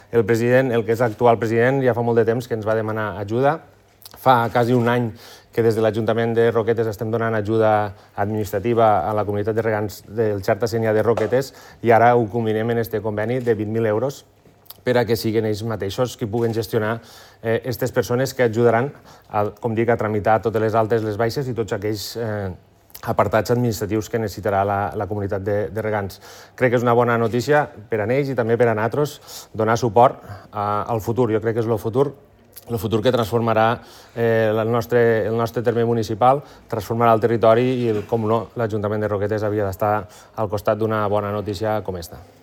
Ivan Garcia, l’alcalde de Roquetes